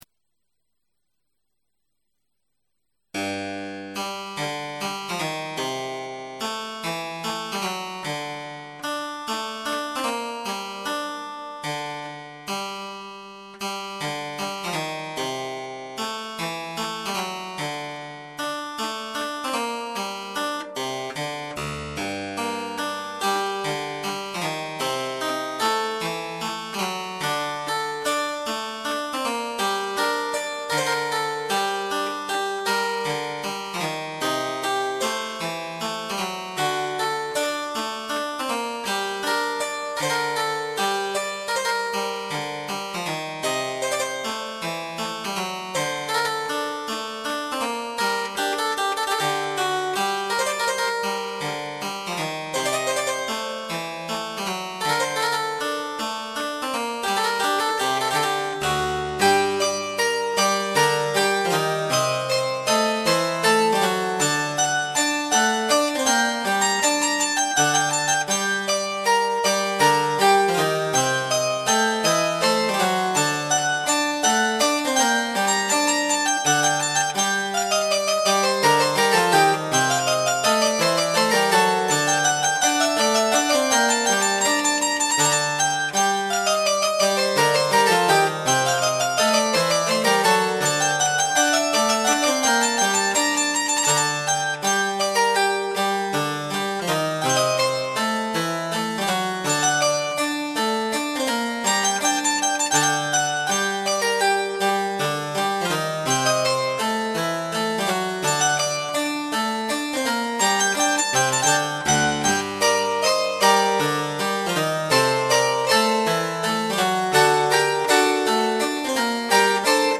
Achtung: Auf der Grundlage teilweise sehr alter und mehrfach überspielter Kassettenaufnahmen
ist die Tonqualität nicht immer optimal.
H. Purcell: Chaconne aus Timon of Athens (Cembalo)             (4`00 / 3,7 MB)  1998, Neufassung 1/2011 (flink)